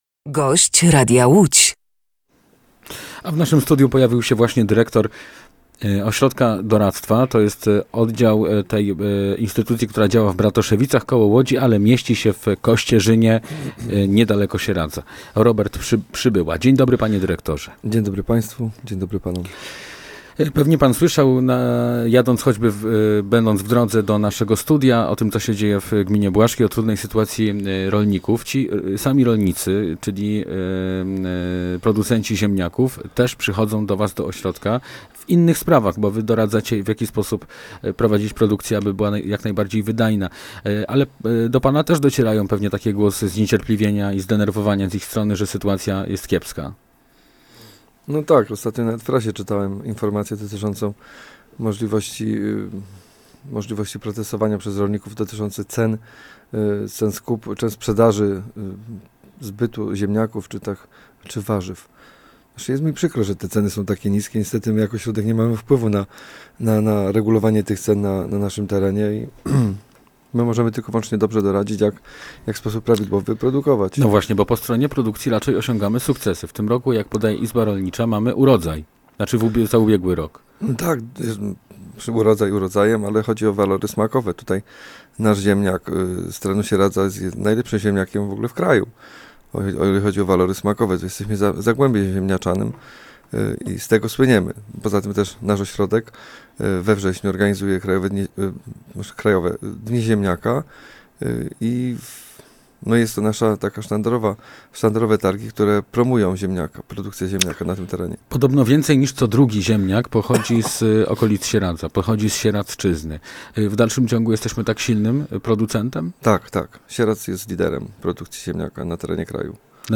Posłuchaj całej rozmowy: Nazwa Plik Autor – brak tytułu – audio (m4a) audio (oga) Warto przeczytać Kolejny transfer Widzewa!